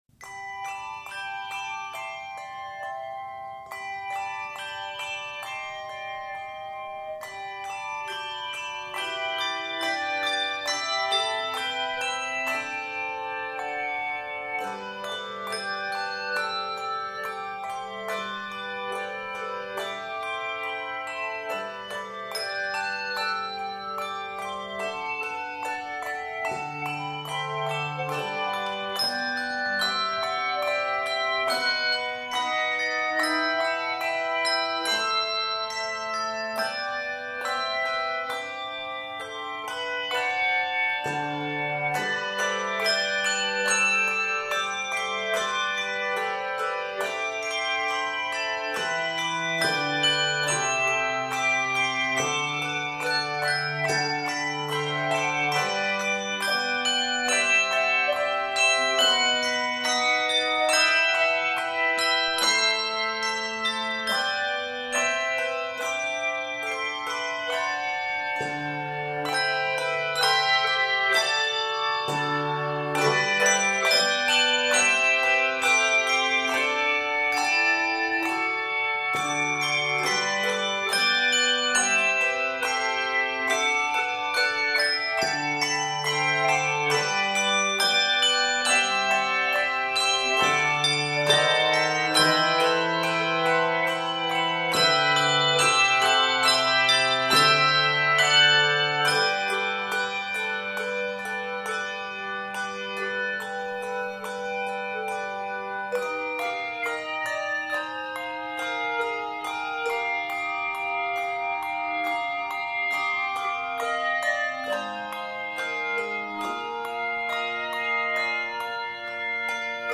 handbell setting
this piece is scored in G Major